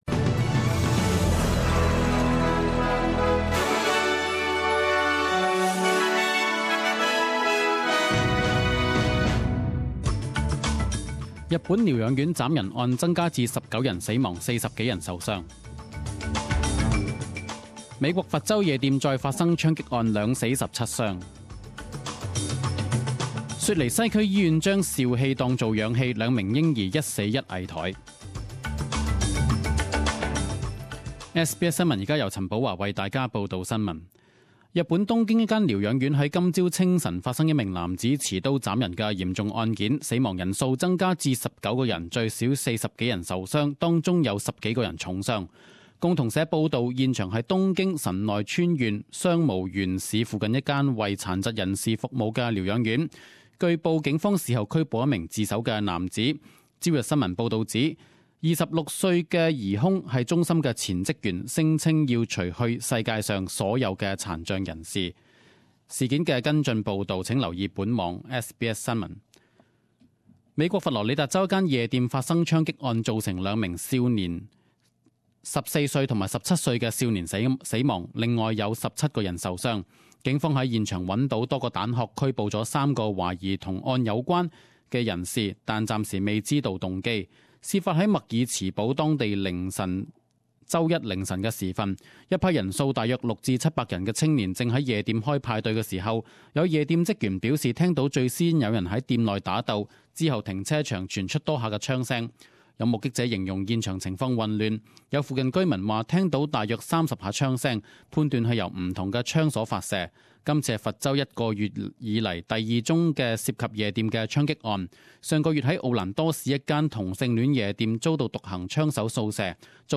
10am News Bulletin 26.07.2016